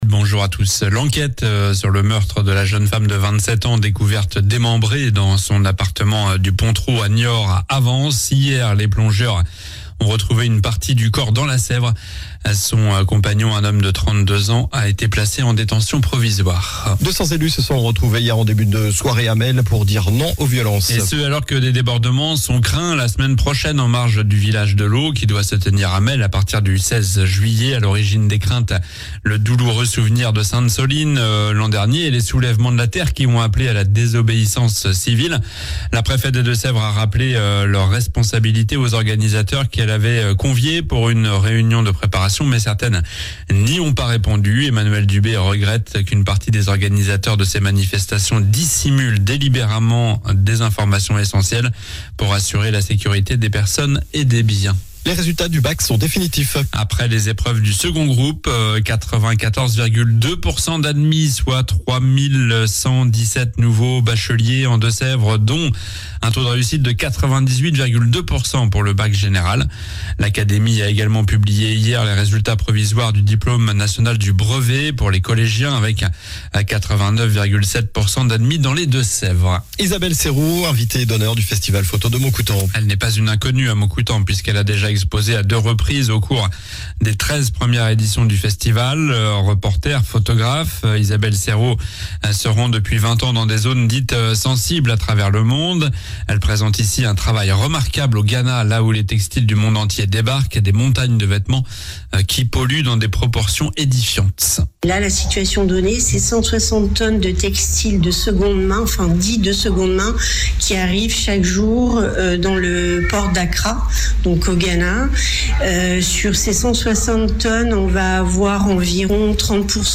Journal du samedi 13 juillet (matin)